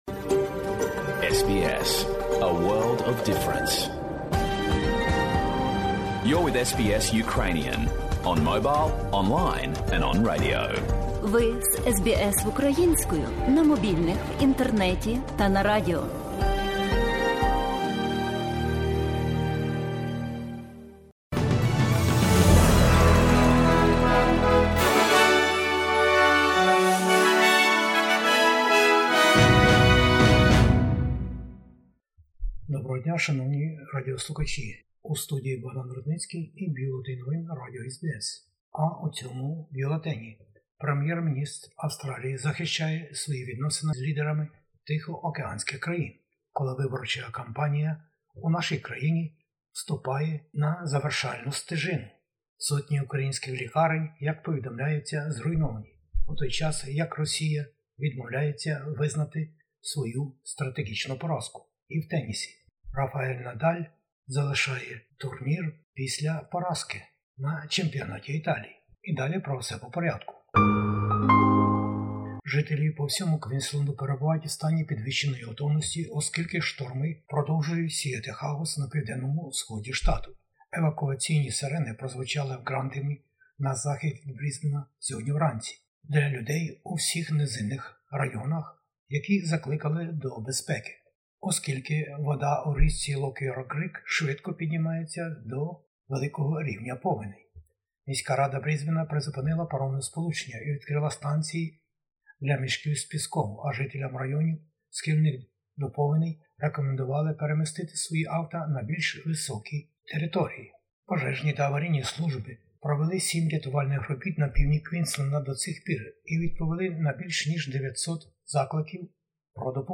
Бюлетень SBS новин українською мовою. Федеральні вибори-2022: дострокове голосування триває по всій Австралії, але головні політичні партії й далі продовжують представляти свої програми, обіцяти майбутні кроки та сперечaтися за майбутнє лідерство. Прeзидент України про війну та її страхіття й жорстокості російських військових щодо цивільних людей та цивільної інфраструктури. Складні погодні умови у Квінсленді.